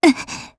Xerah-Vox_Damage_jp_02.wav